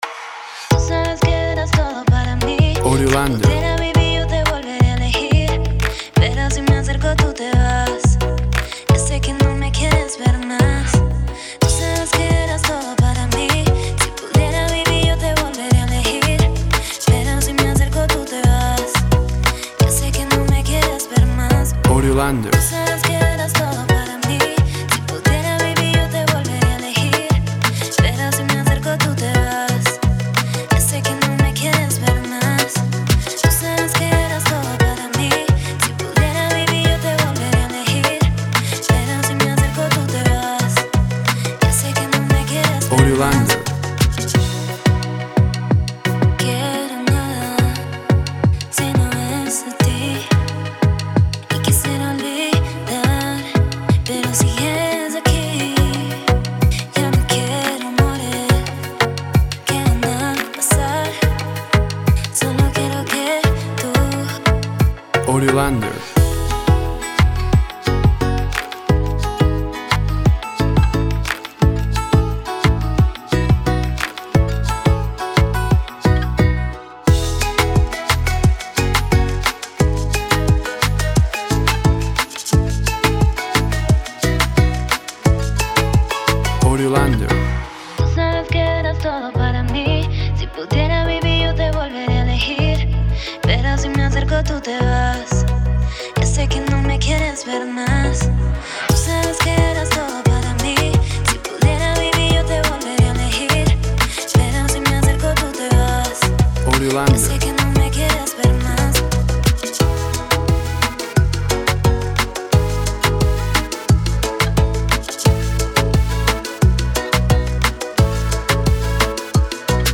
Tempo (BPM) 80